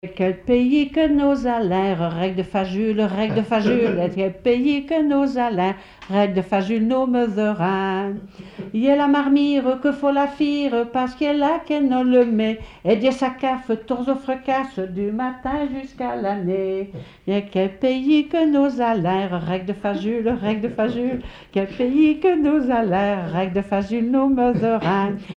Saint-Julien-en-Genevois
Pièce musicale inédite